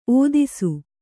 ♪ ōdisu